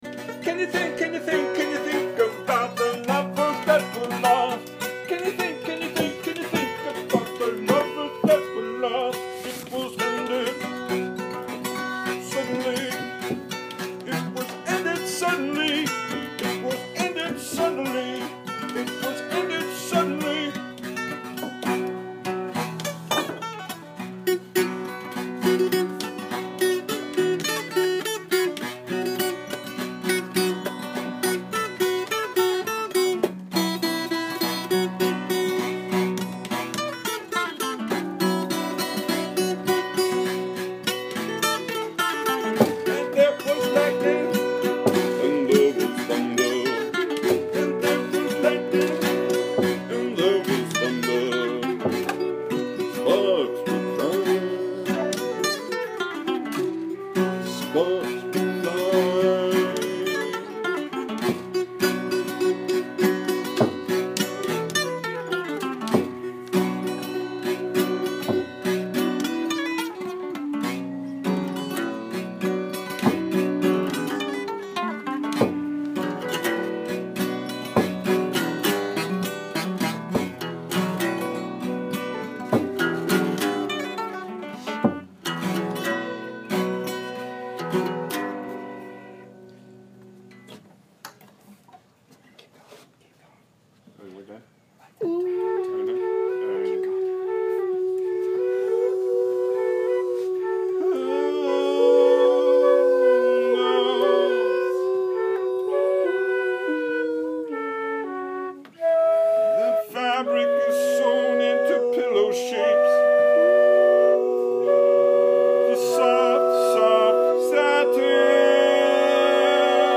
ALL MUSIC IS IMPROVISED ON SITE
sitar/voice
alto flute
Bongos